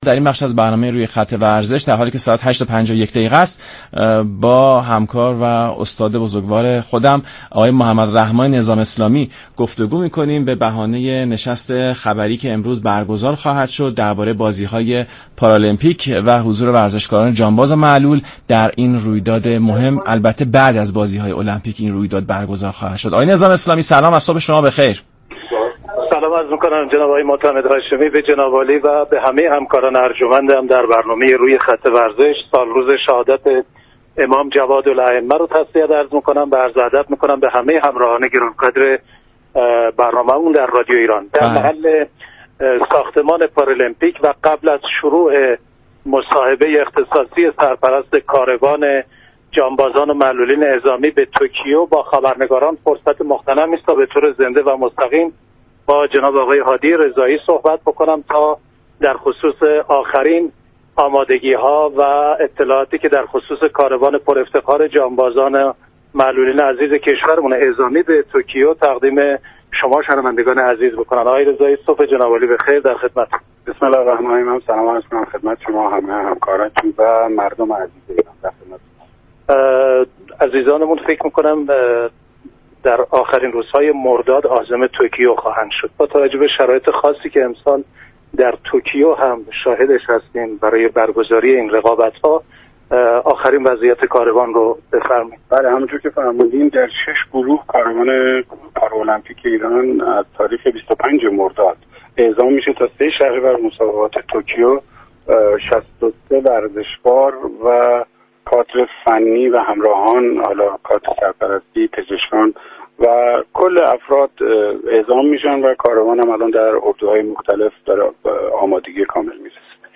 گفت و گوی اختصاصی